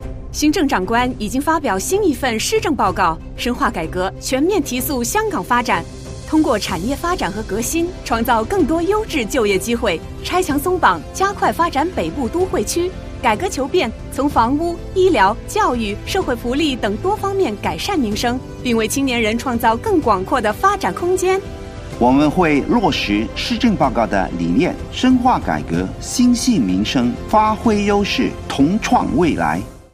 电台宣传广播